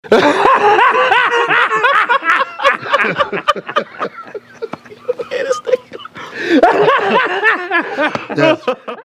Play Laugh Charlie Murphy - SoundBoardGuy
laugh-charlie-murphy.mp3